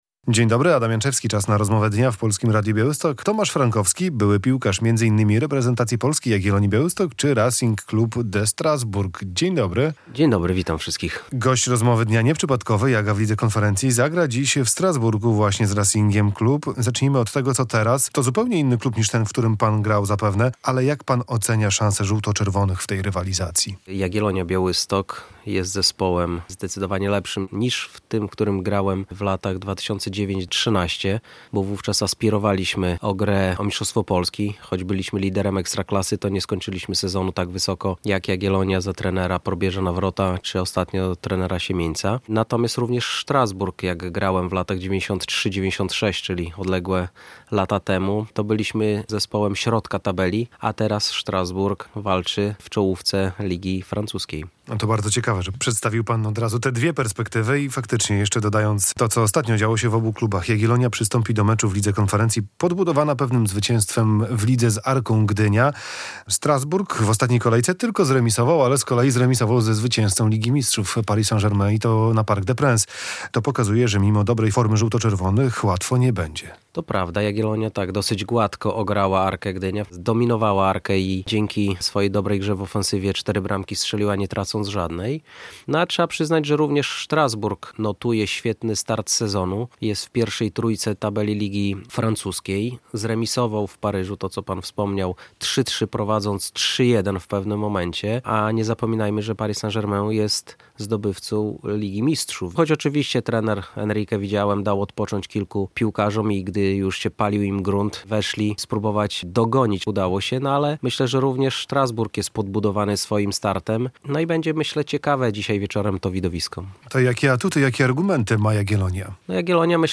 Radio Białystok | Gość | Tomasz Frankowski - były piłkarz Jagiellonii Białystok i RC Strasbourg